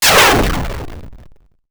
8 bits Elements
missil_1.wav